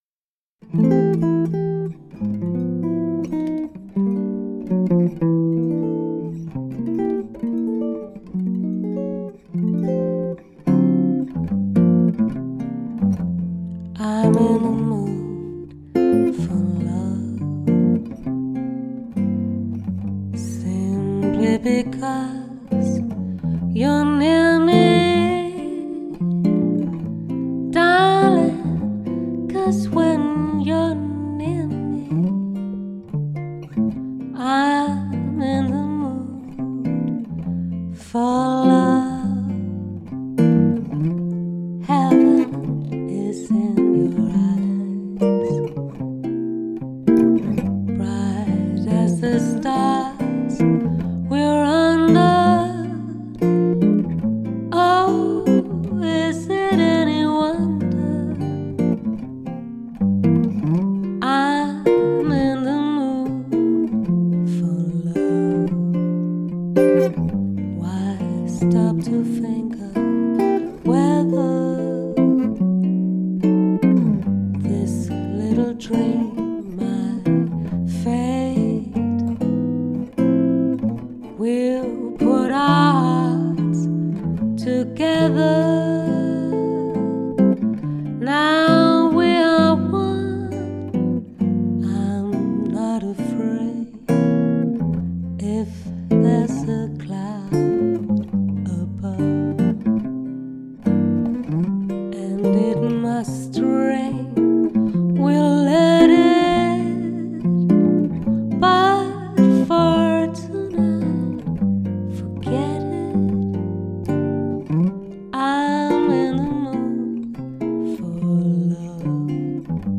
Gitarre/Loops